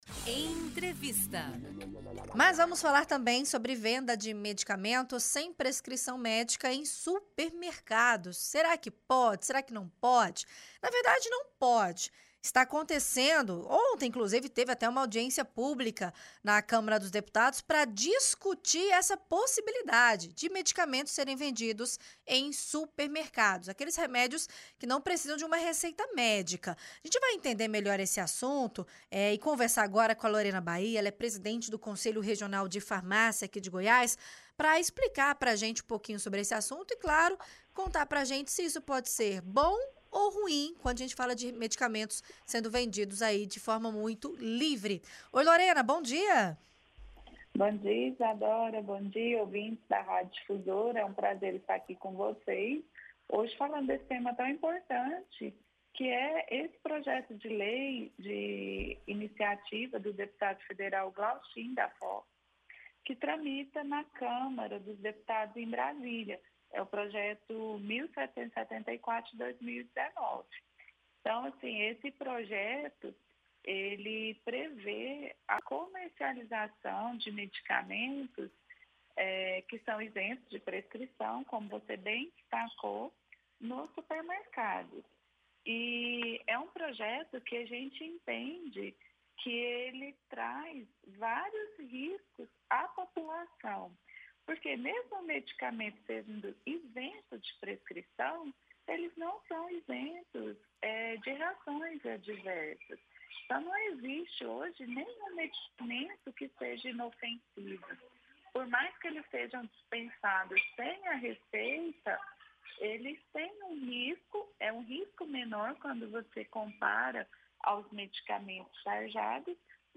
concedeu uma entrevista à Rádio Difusora